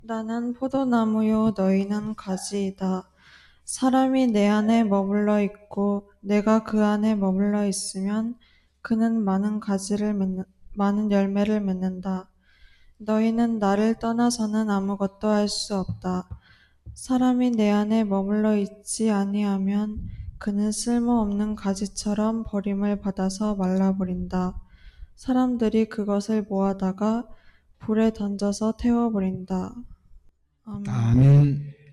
성경봉독